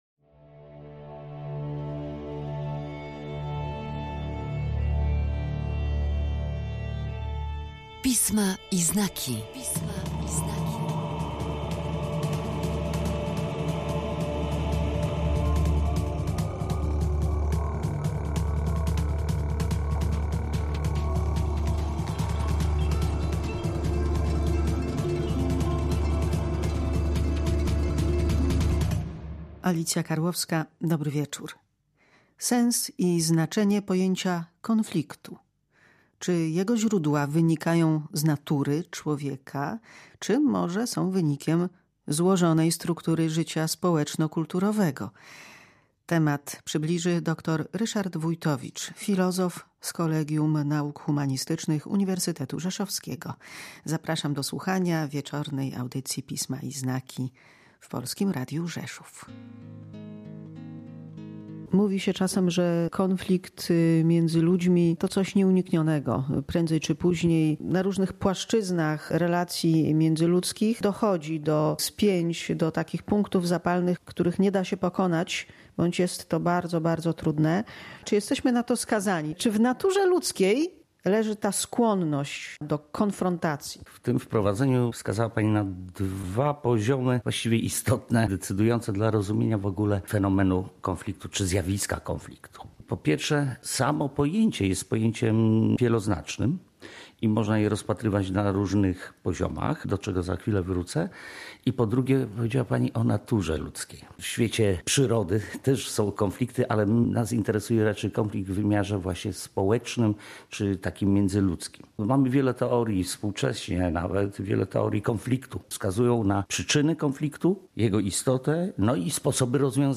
Pisma i znaki. Rozmowa o znaczeniu pojęcia konfliktu